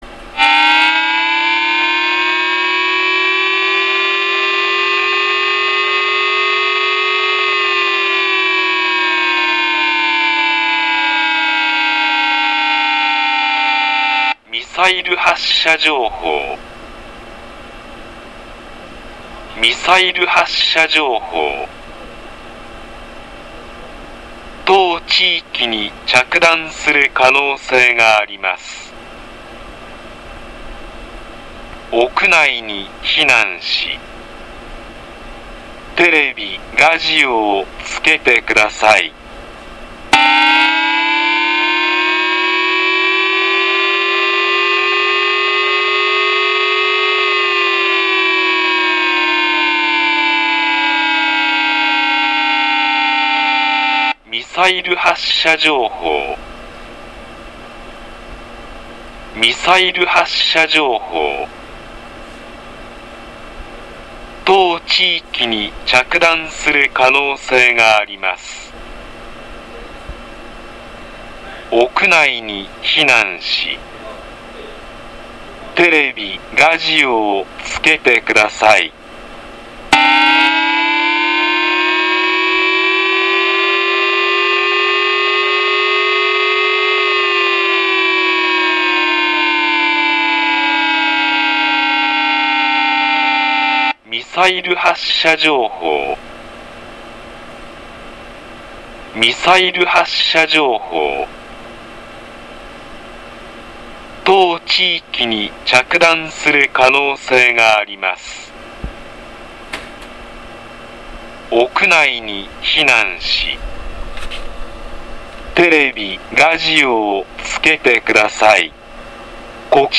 国（消防庁）から緊急地震速報やテロ攻撃などに関する緊急情報が、衛星通信ネットワークを用いて瞬時に送信され、それを防災行政無線の屋外スピーカーから自動放送してお知らせするシステムです。
有事情報   弾道ミサイル攻撃に係る警報の場合
（有事サイレン14秒鳴ります。）
（チャイム音が鳴ります。）